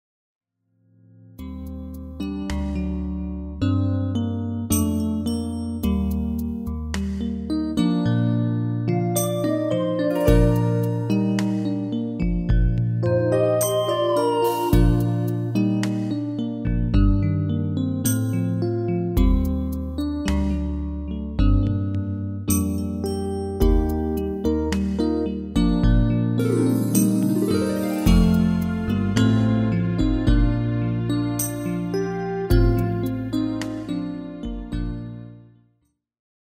Demo/Koop midifile
Genre: Ballads & Romantisch
- GM = General Midi level 1
- Géén tekst
- Géén vocal harmony tracks